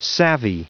Prononciation du mot savvy en anglais (fichier audio)
Prononciation du mot : savvy